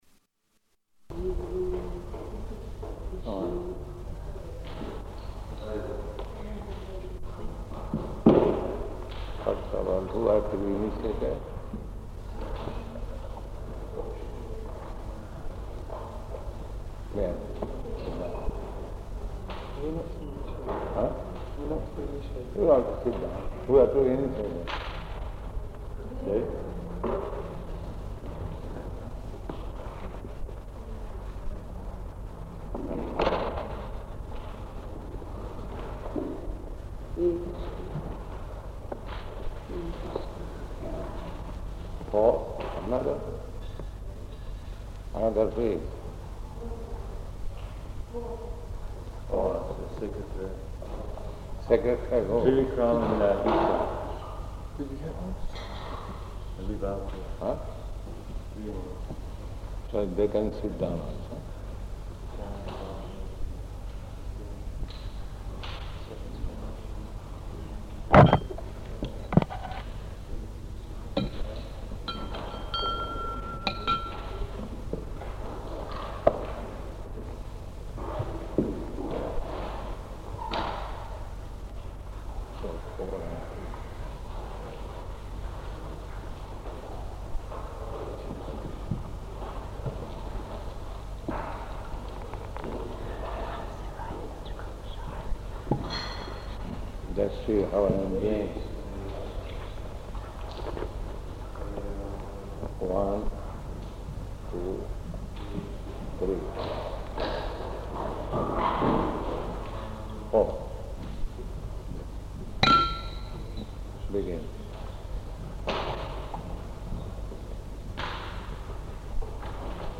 Initiations & Wedding --:-- --:-- Type: Initiation Dated: October 1st 1969 Location: London Audio file: 691001IN-LONDON.mp3 Devotee: [background] Śrī Guru, Kṛṣṇa.